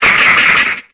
Cri de Ludicolo dans Pokémon Épée et Bouclier.